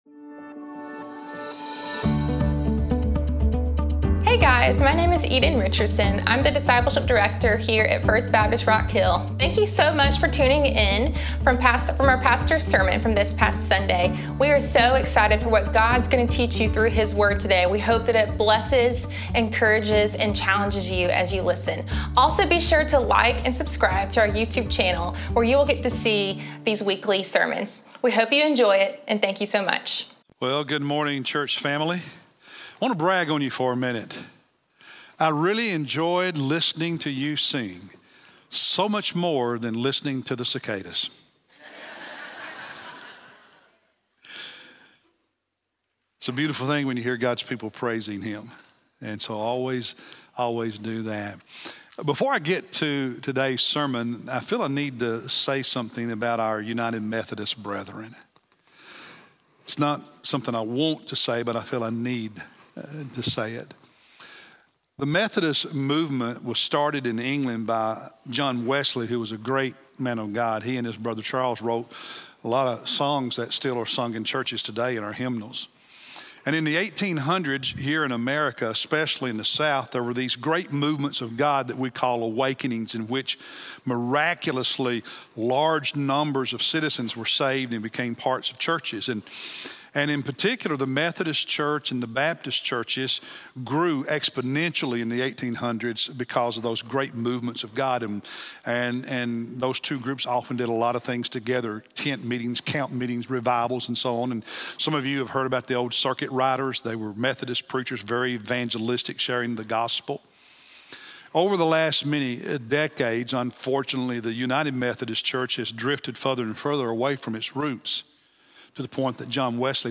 May-5-Sermon-1.mp3